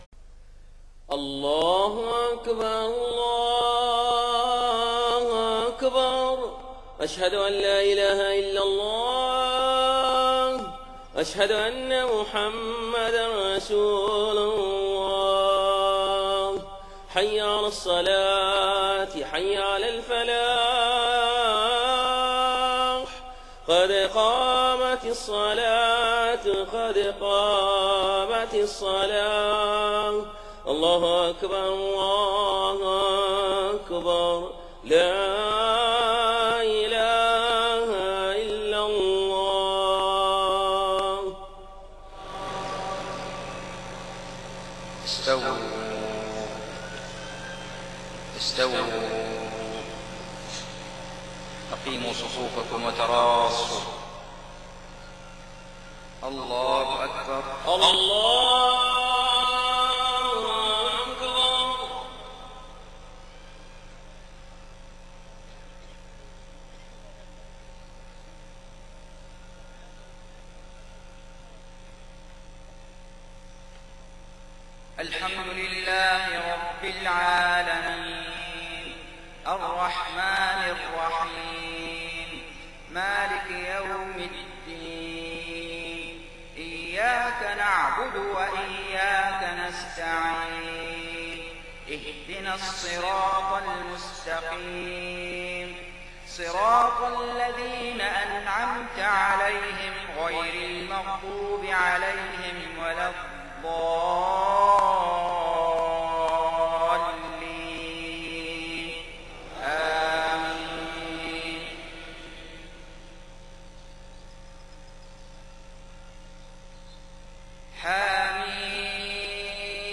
صلاة العشاء 26 محرم 1430هـ فواتح سورة فصلت 1-18 > 1430 🕋 > الفروض - تلاوات الحرمين